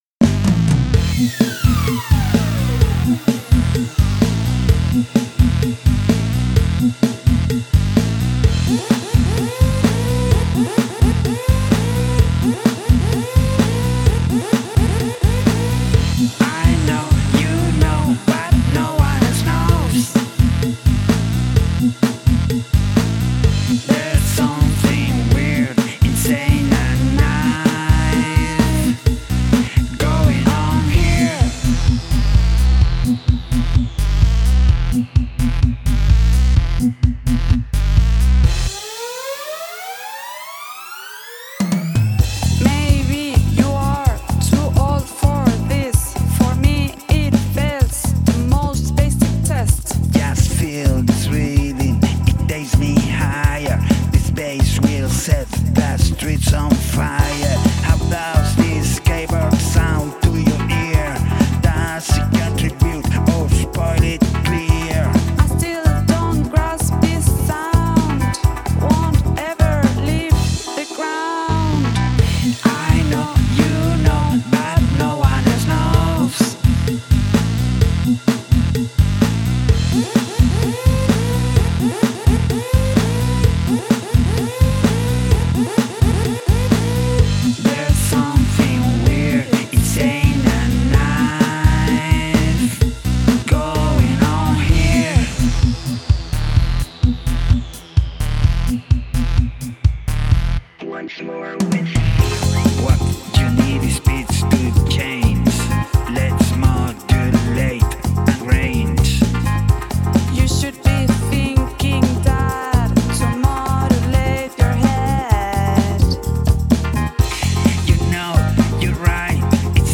The doubled vocals are tasty.